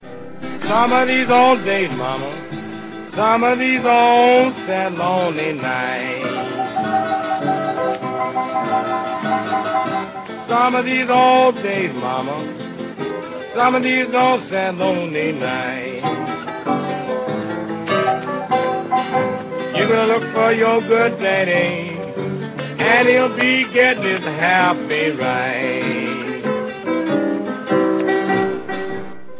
Пианист и вокалист